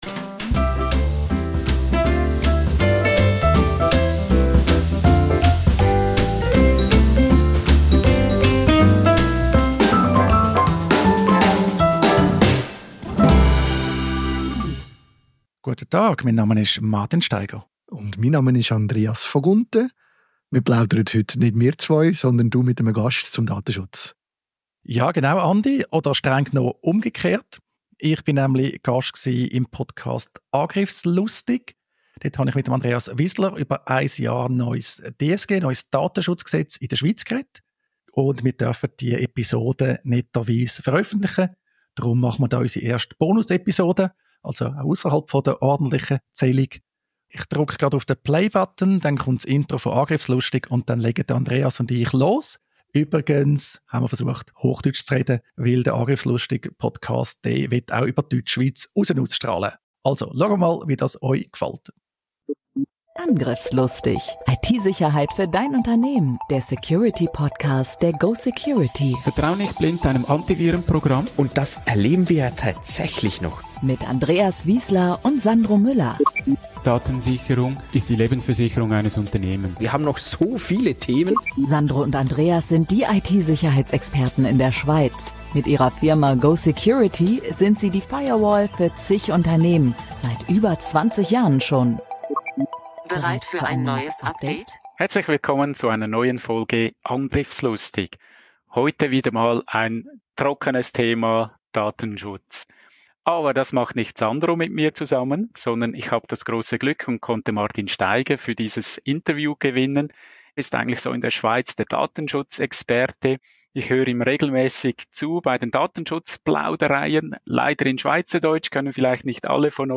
Wir veröffentlichen das Gespräch als erste Bonus-Episode der «Datenschutz-Plaudereien».